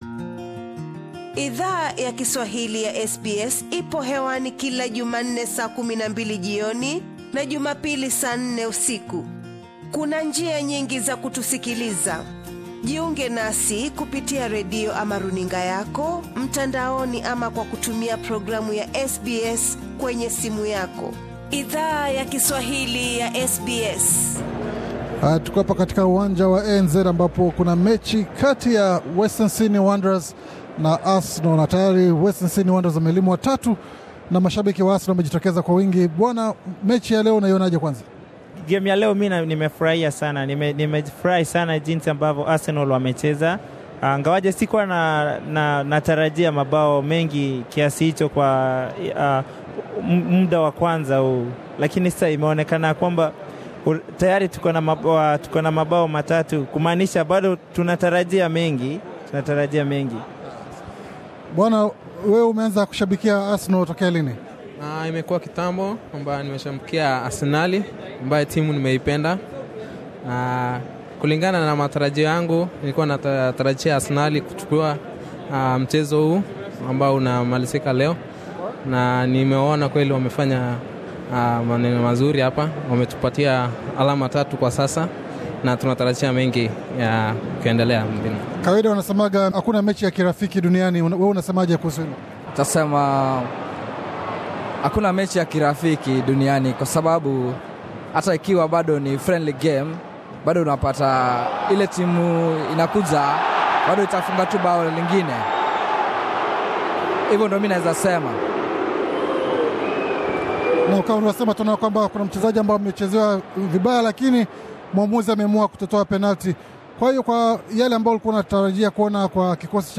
Mashabiki wa Arsenal FC walijitokeza kwa ma mia yama elfu kutazama mechi ya vigogo hao wa ligi kuu ya Uingereza mjini Sydney dhidi ya Western Sydney Wanderers. SBS Swahili ilihudhuria mechi hiyo naku zungumza na baadhi ya mashabiki hao, wengi wao ambao ndoto zao zaku hudhuria mechi za Arsenal zilipata jawabu wiki hii mjini Sydney, Australia.